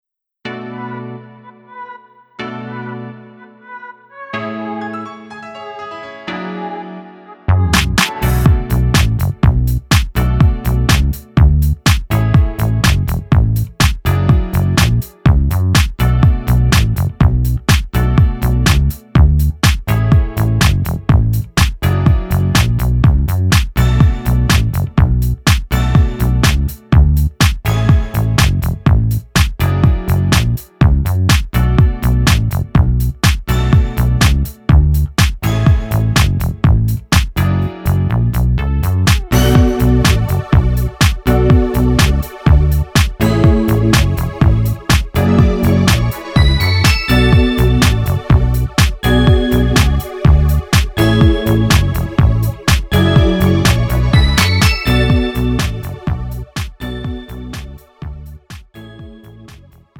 음정 원키
장르 pop 구분 Pro MR